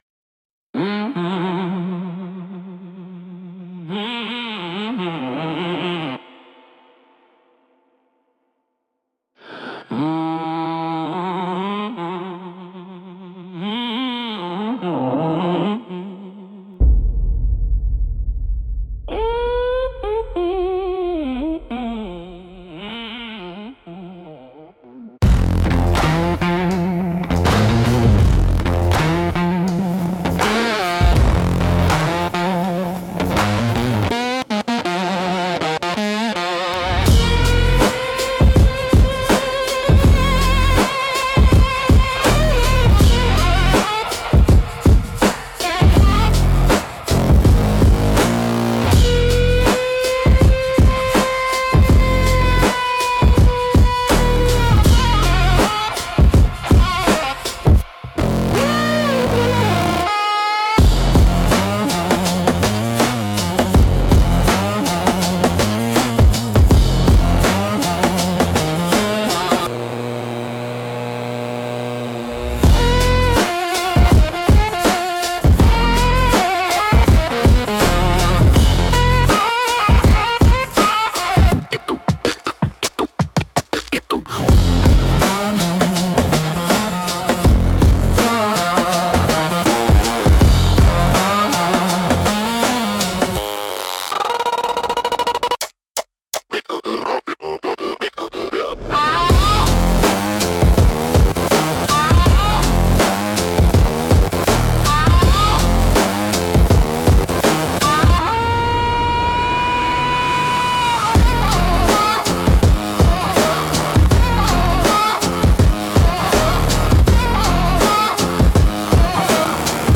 Instrumental - Low-End Gospel - 2.15